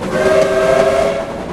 Steam